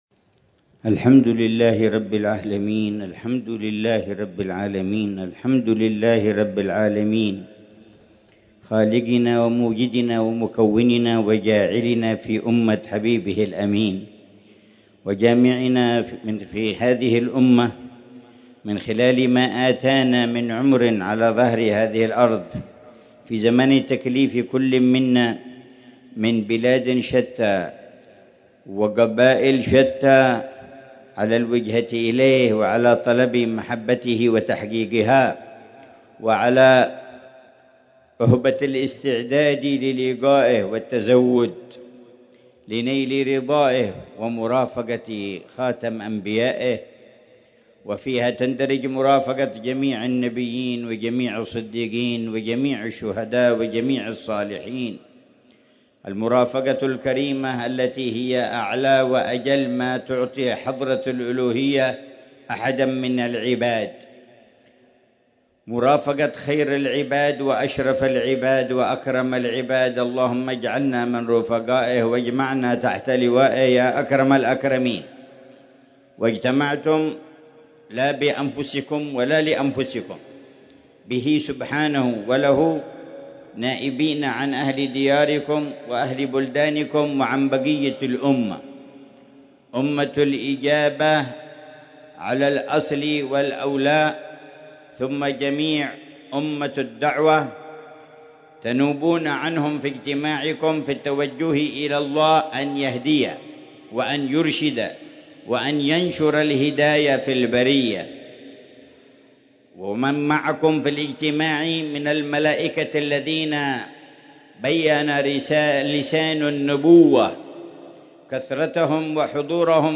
محاضرة
في احتفالات أهل المحبة والوفاء بذكرى المولد النبوي في دار المصطفى